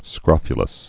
(skrŏfyə-ləs)